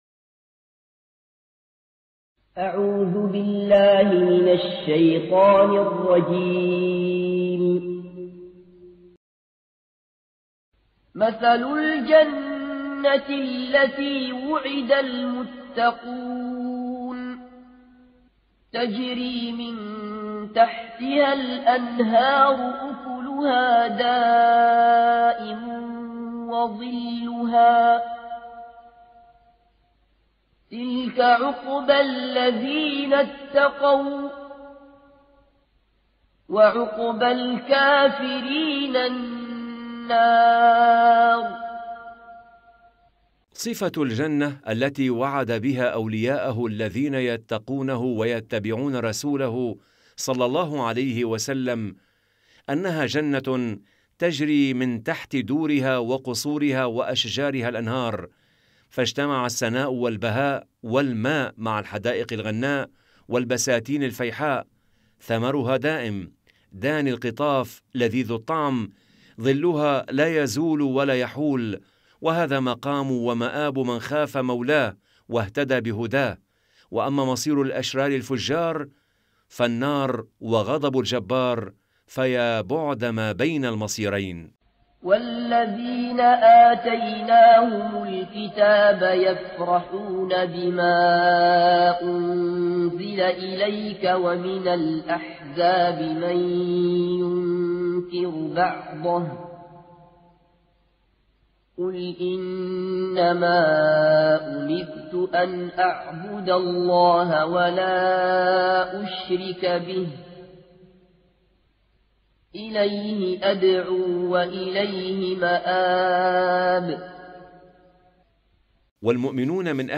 مجلدات التفسير تلاوة تعليمية للقرآن الكريم مع التفسير الميسر